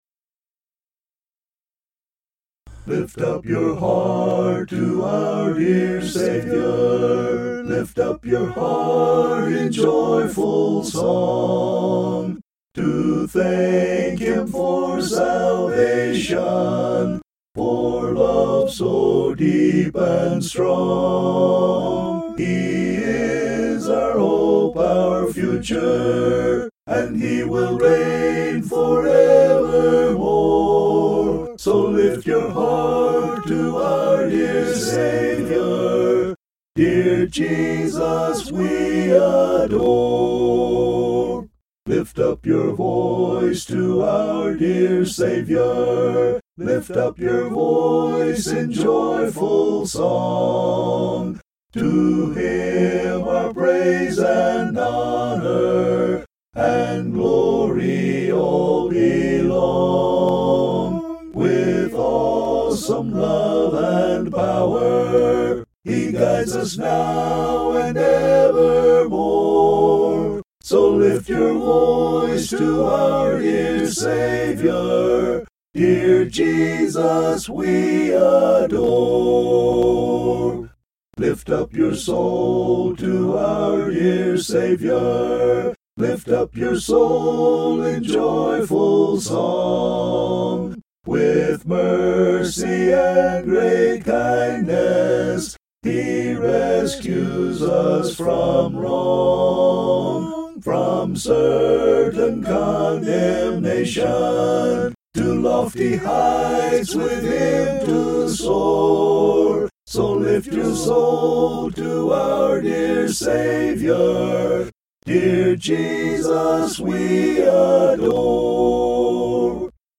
(An original hymn)
vocals